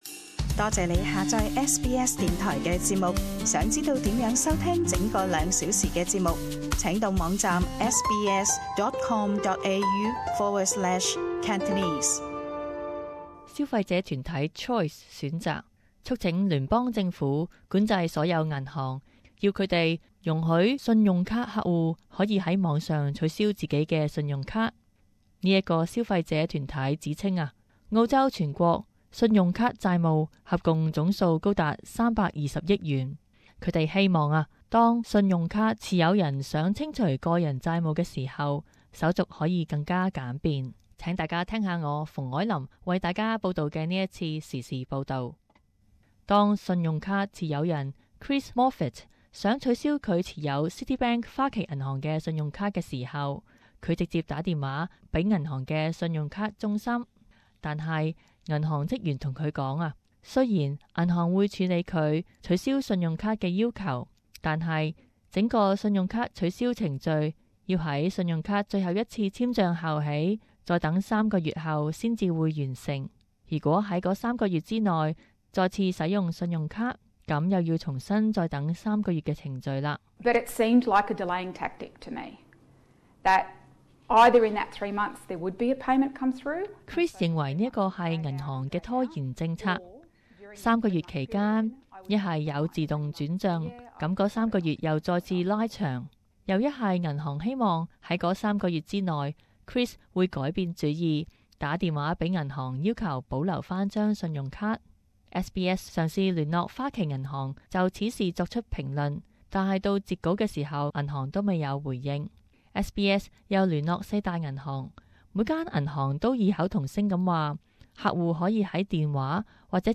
【時事報導】想取消信用卡不容易